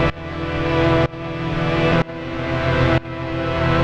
GnS_Pad-MiscA1:2_125-E.wav